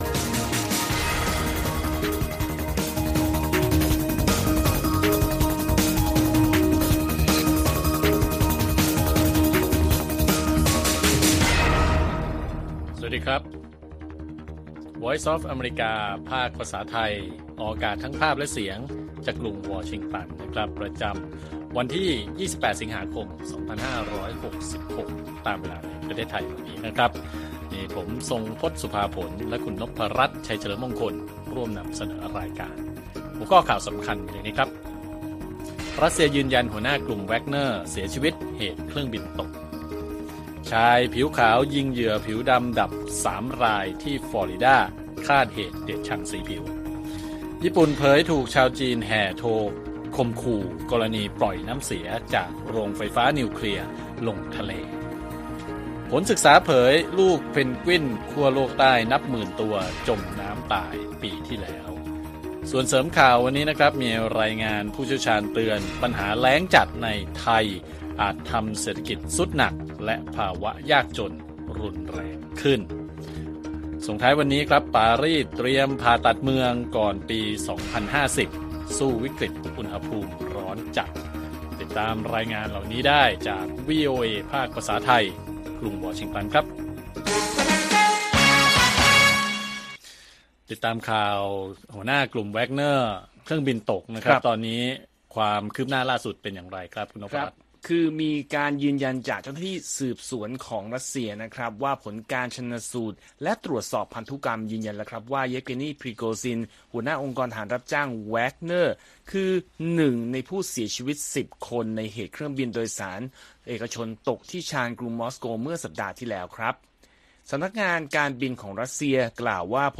ข่าวสดสายตรงจากวีโอเอไทย 6:30 – 7:00 น. วันที่ 28 ส.ค. 2566